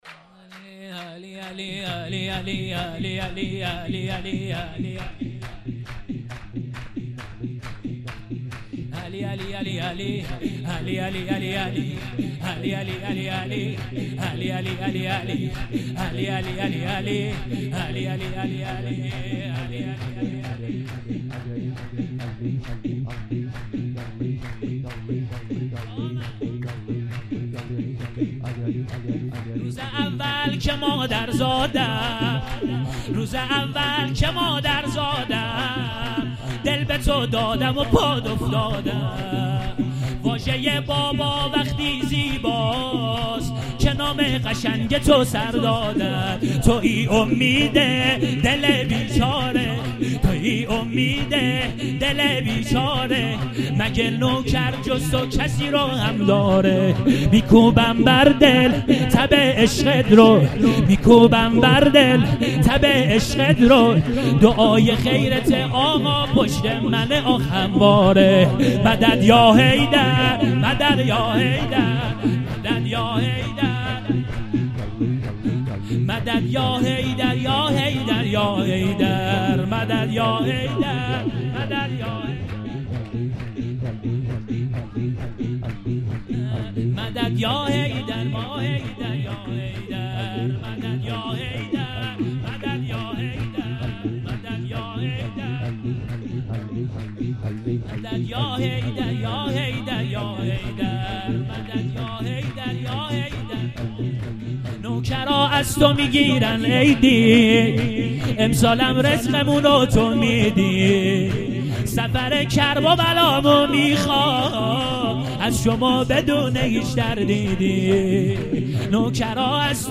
22 0 جلسه هفتگی 11 آذر 96
سبک مولودی جدید